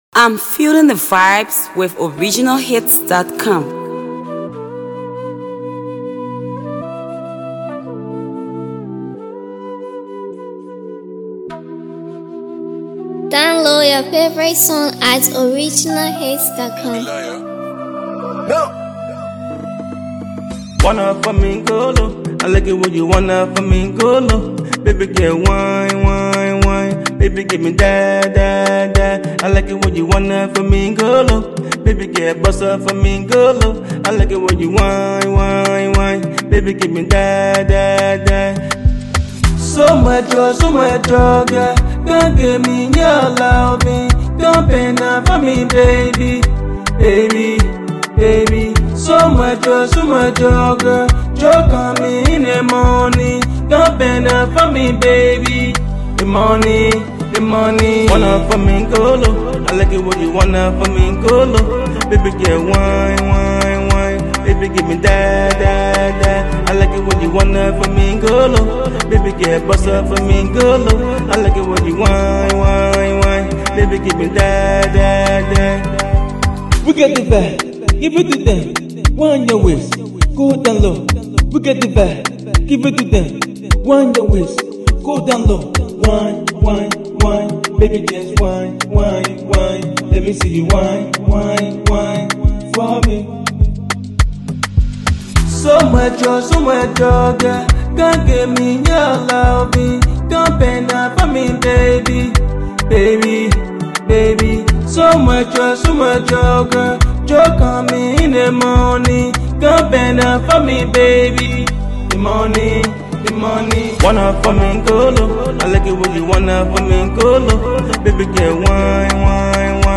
Catchy jam